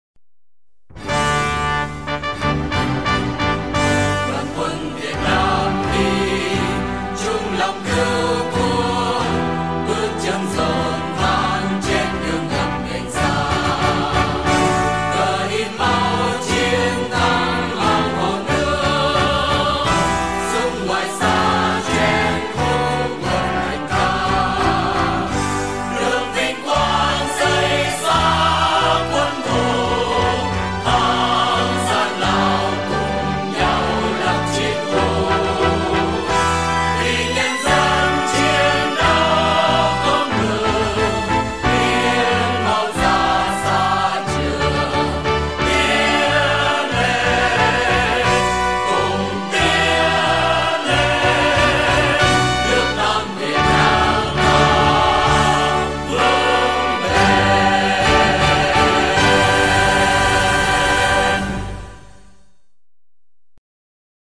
File nhạc có lời